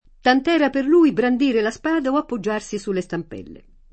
tant $ra per l2i brand&re la Sp#da o appoJJ#rSi Sulle Stamp$lle] (F. Martini); una campagna dove gli alberi apparivano piccini tant’era immensa [